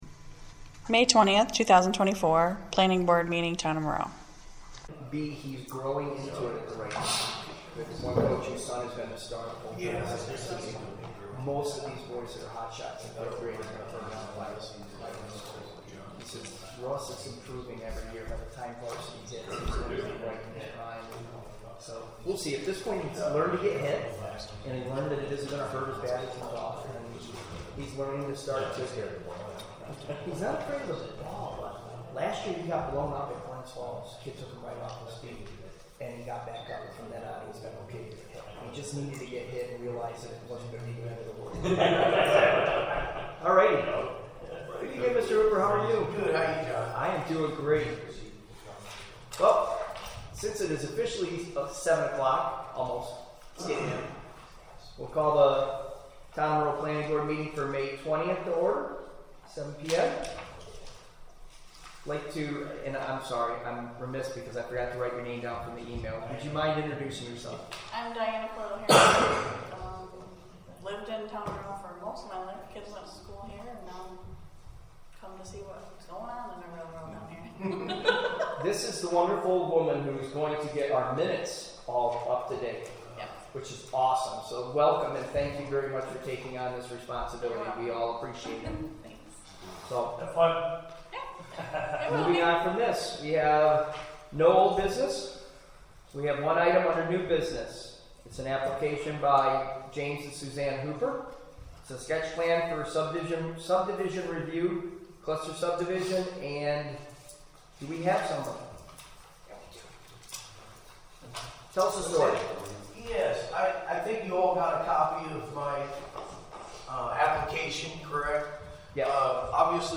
Planning Board Audio